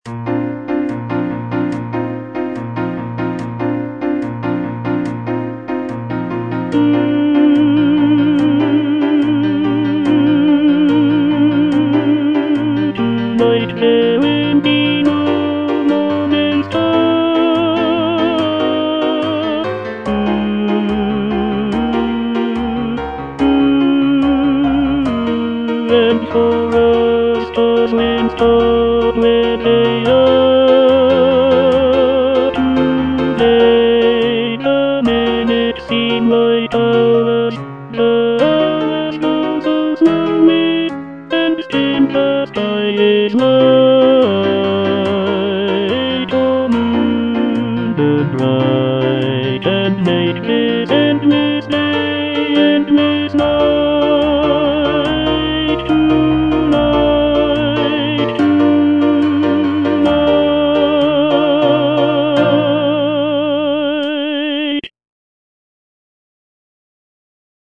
Tenor (Voice with metronome) Ads stop